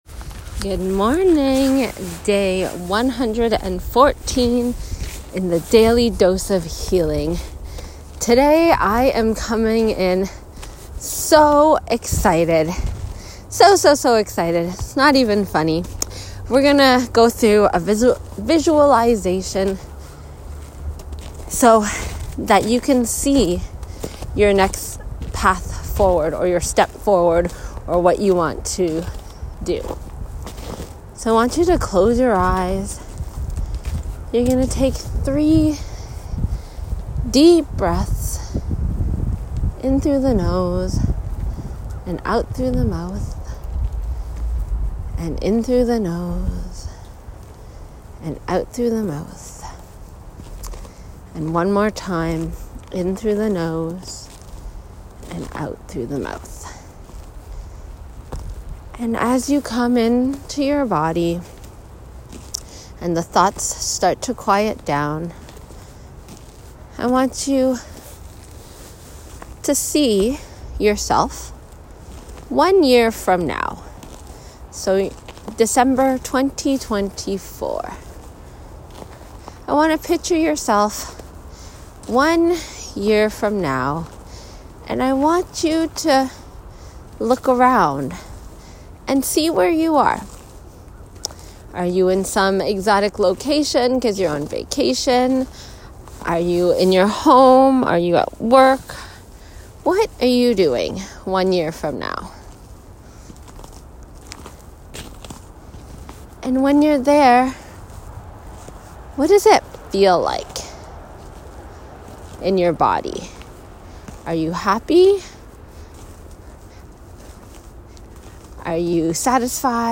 The following meditation takes you through the visualization process to begin the manifestation process.